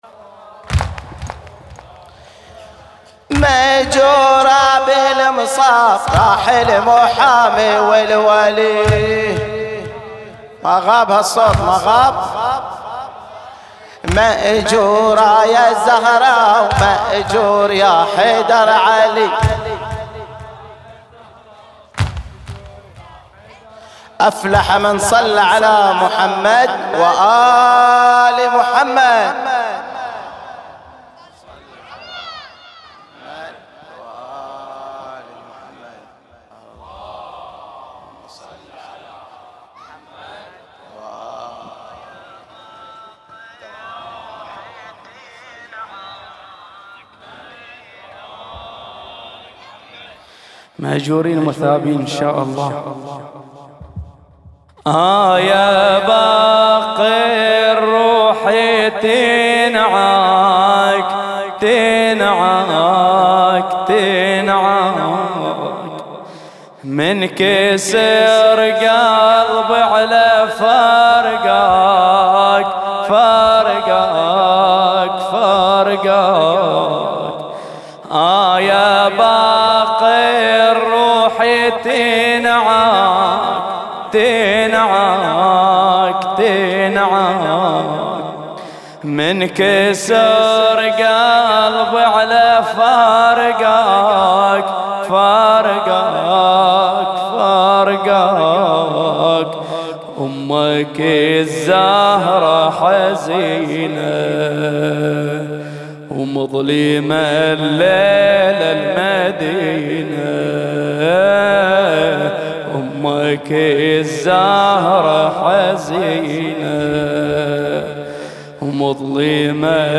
تغطية شاملة: موكب العزاء ليلة وفاة الإمام محمد الباقر ع 1440هـ
موكب العزاء